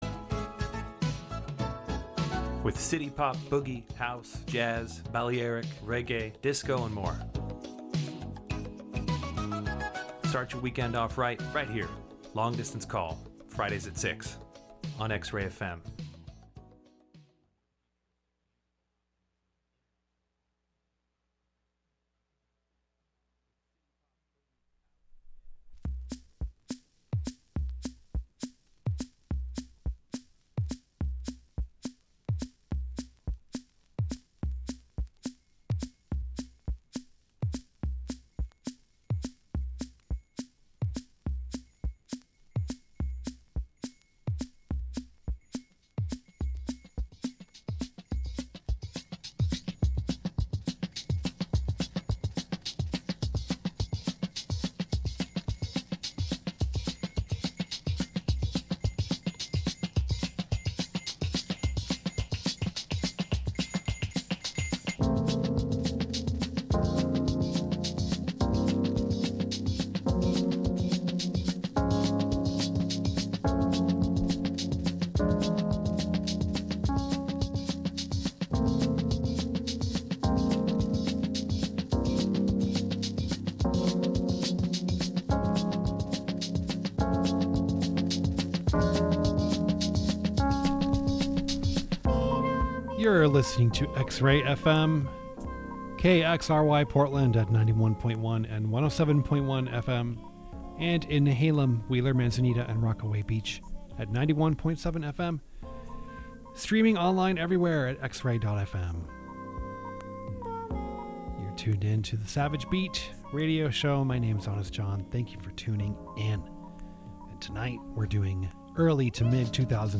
SAVAGE BEAT is garage, surf, proto-punk and a little more of the like plus some out of character stuff from time to time! Weirdo screamers and trashy creepers.